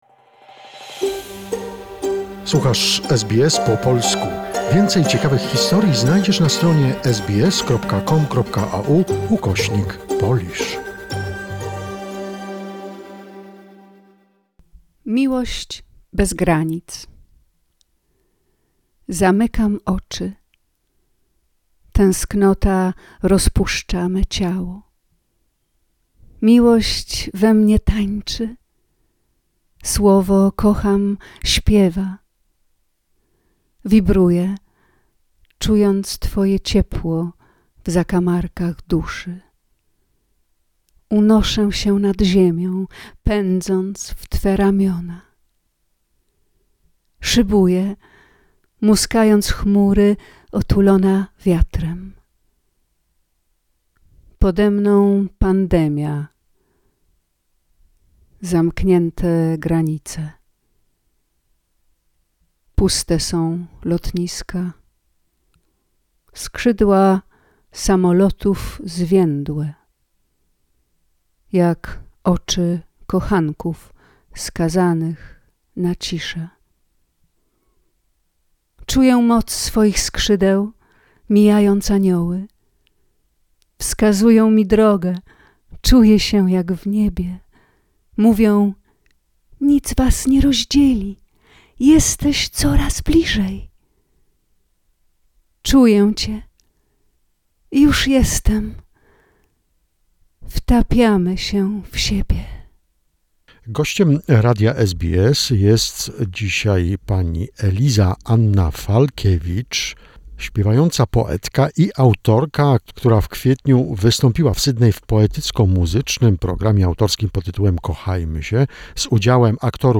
Part two of the interview